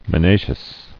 [mi·na·cious]